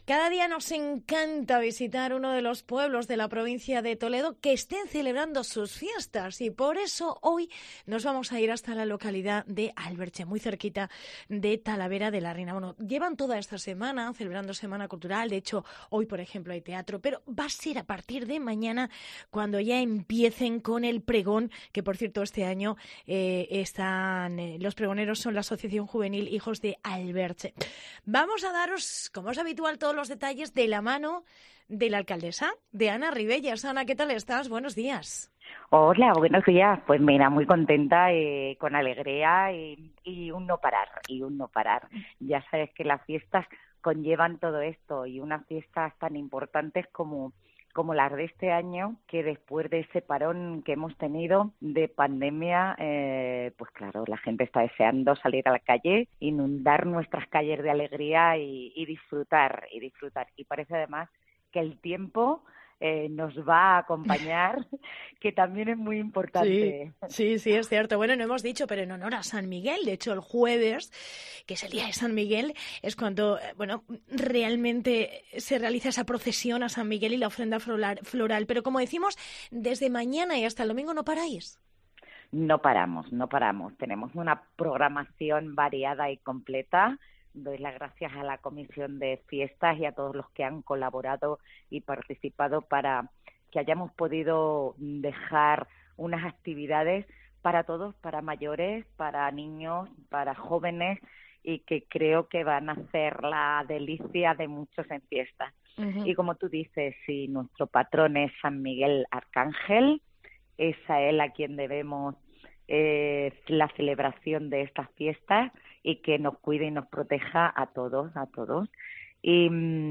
Entrevista a Ana Rivelles, alcaldesa de Alberche